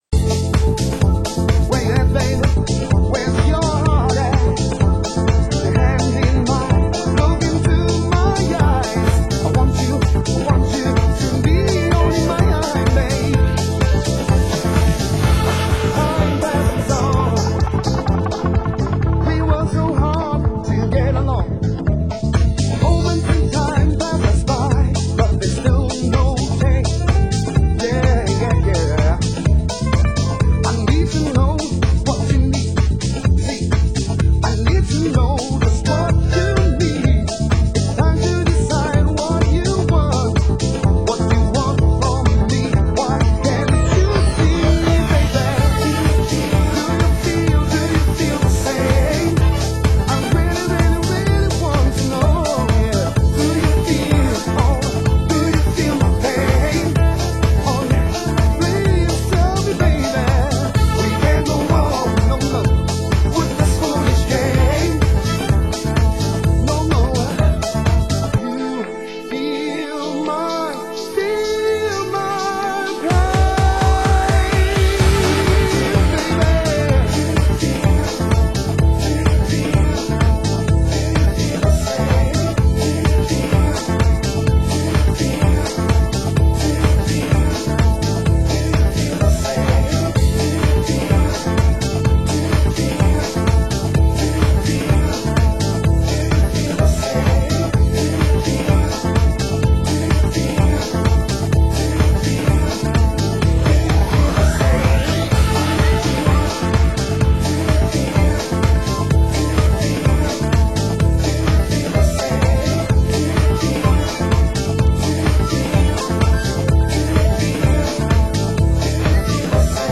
Genre Euro House